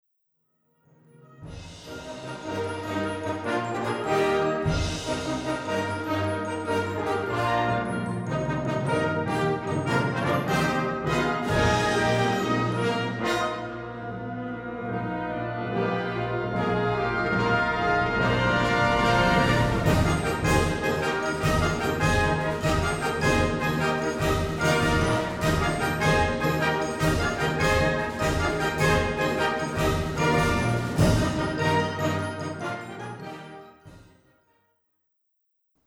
Gattung: Konzertwerk
Besetzung: Blasorchester